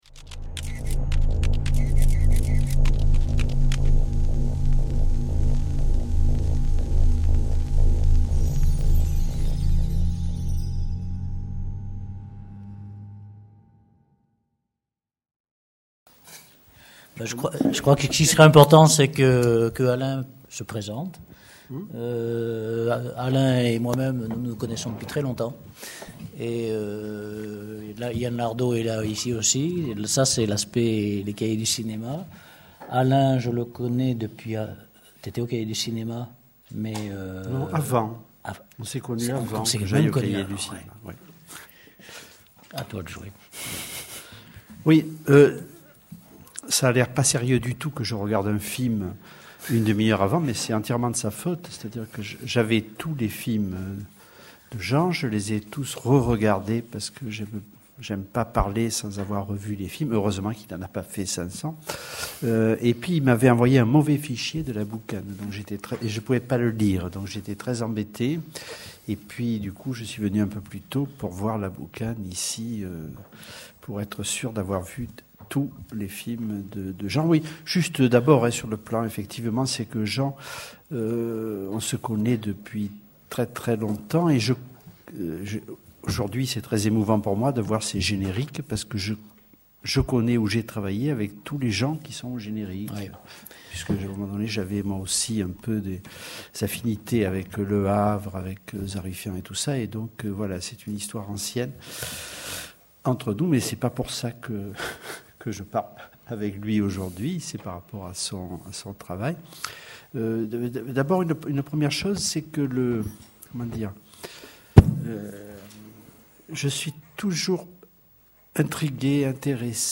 Master classe Jean Gaumy, enregistré le 23 novembre 2013 à l'EHESS.
Rencontre avec Jean Gaumy, cinéaste et photographe de l’agence Magnum, animée par le critique et cinéaste Alain Bergala.